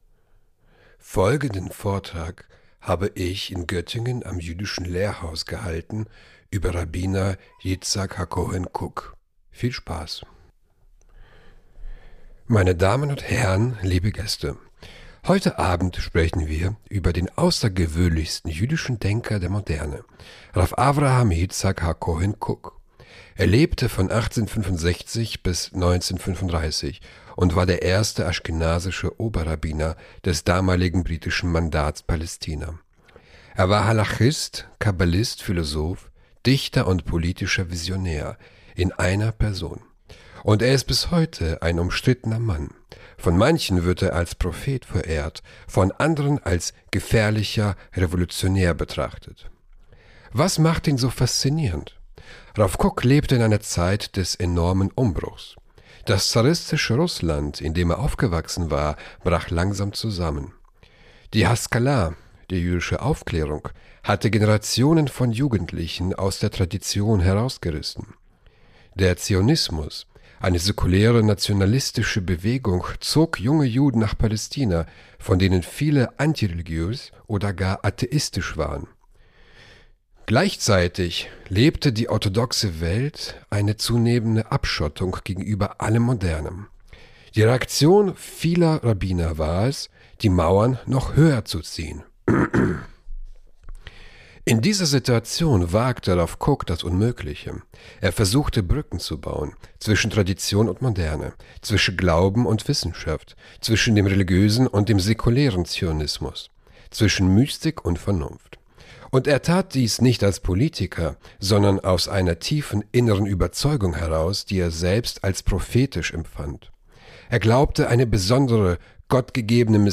Kurzer Vortrag über Rav Kook im jüdischen Lehrhaus zu Göttingen